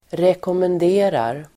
Uttal: [rekåmend'e:rar]